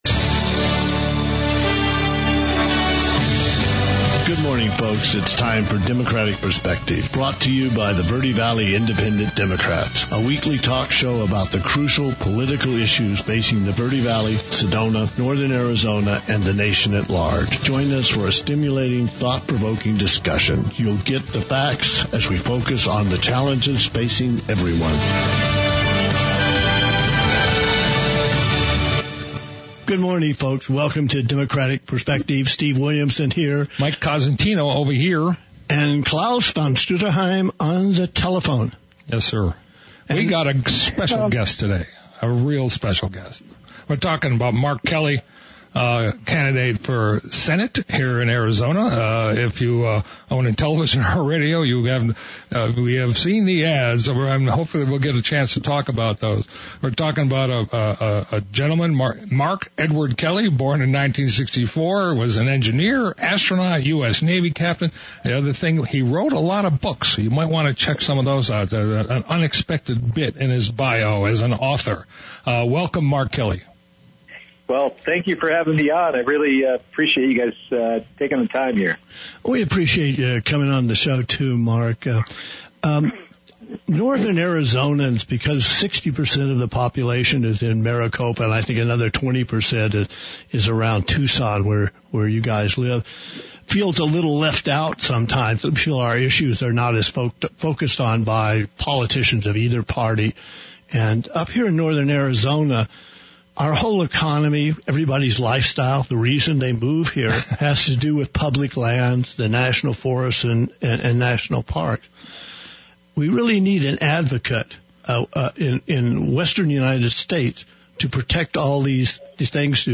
October 5th Interview on KAZM